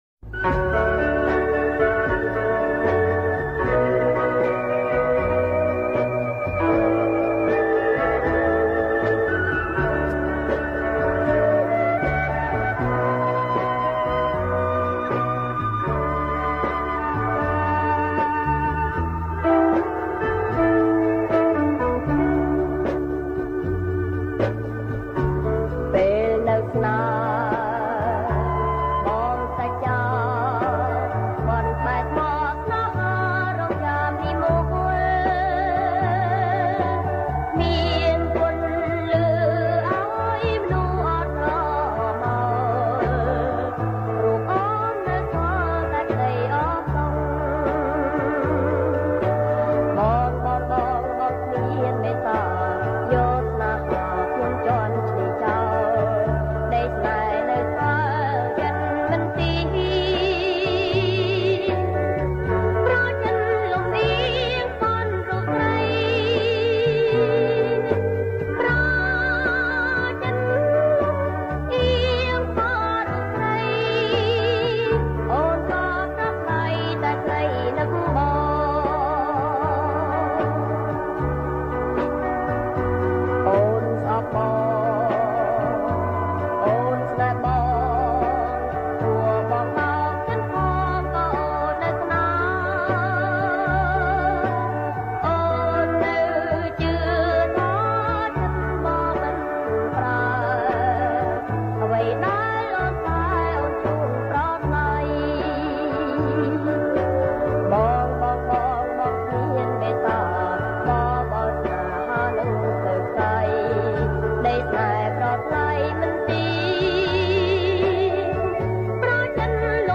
• បទភ្លេង បរទេស
• ប្រគំជាចង្វាក់ Slow Rock